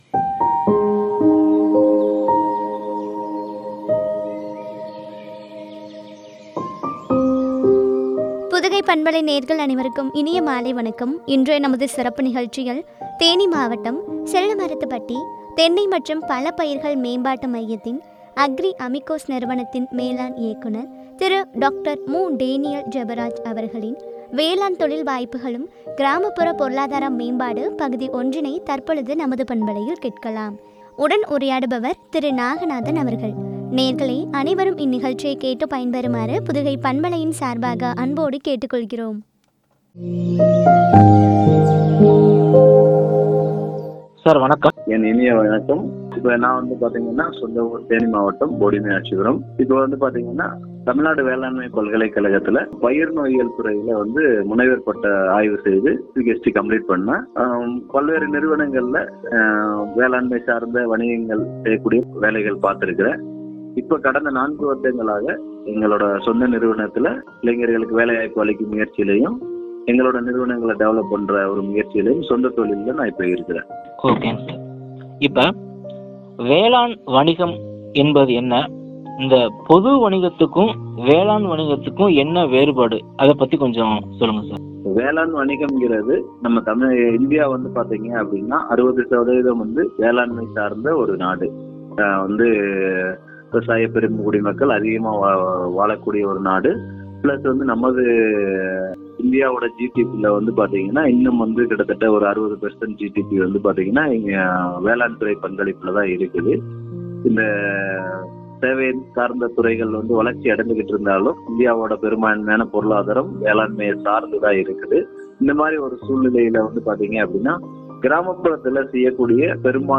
பற்றிய உரையாடல்.